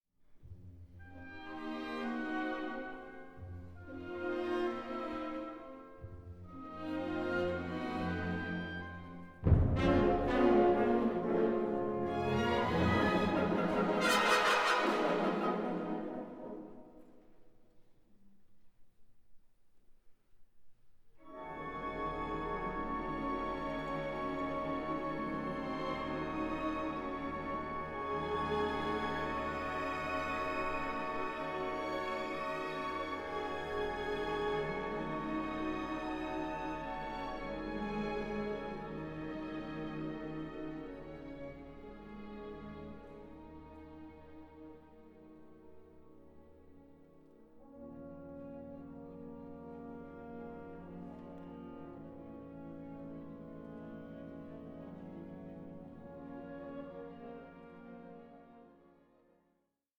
Sensuous, lushly evocative and intricately constructed